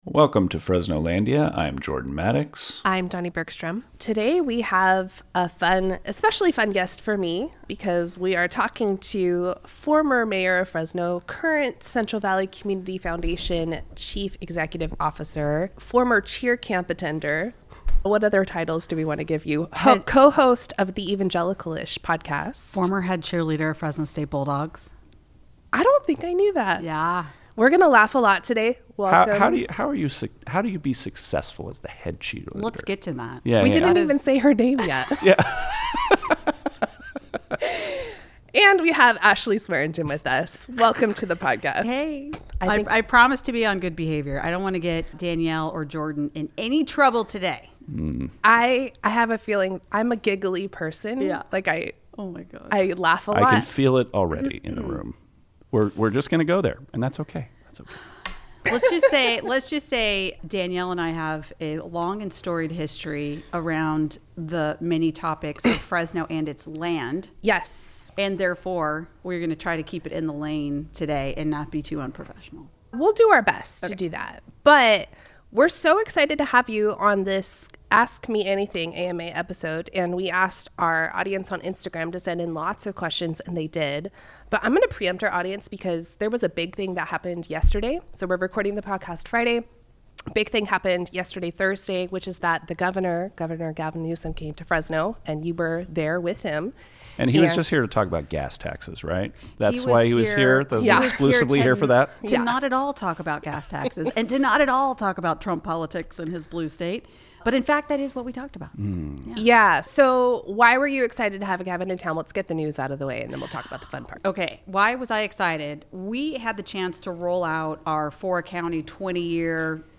AMA Conversation with Ashley Swearengin, Former Mayor and CVCF CEO